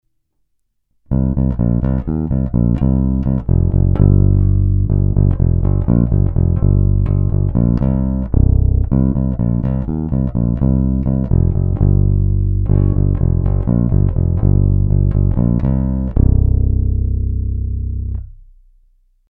Brutálně konkrétní masívní zvuk.
Není-li uvedeno jinak, následující nahrávky jsou vyvedeny rovnou do zvukové karty a s plně otevřenou tónovou clonou, následně jsou jen normalizovány, jinak ponechány bez úprav.